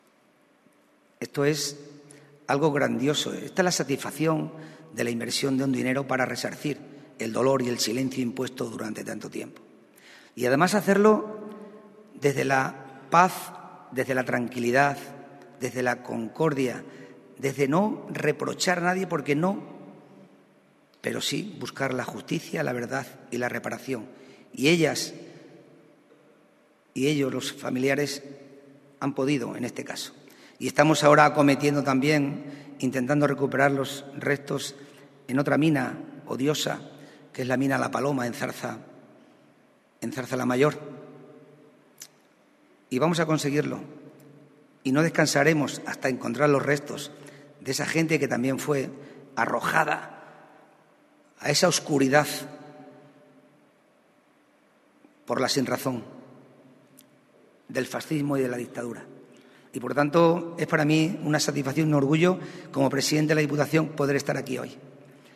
CORTES DE VOZ
Miguel Ángel Morales 2_Presidente de la Diputación de Cáceres